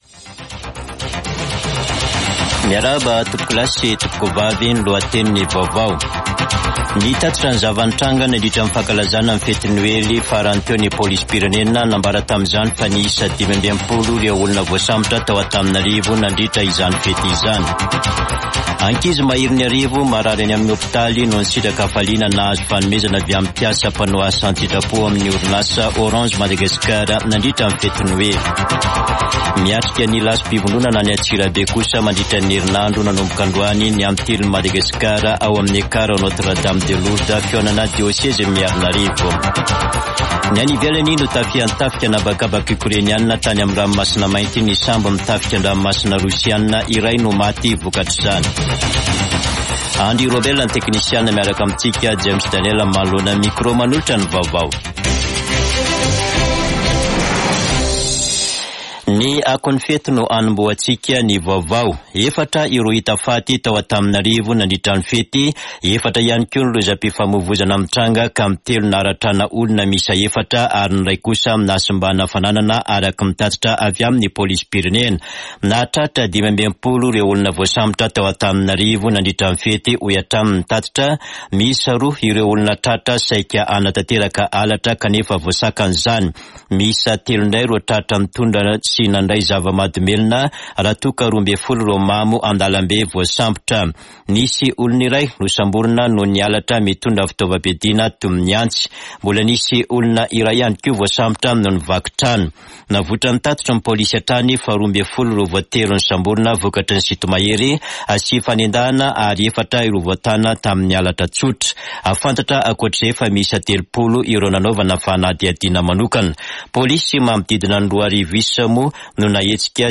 [Vaovao hariva] Talata 26 desambra 2023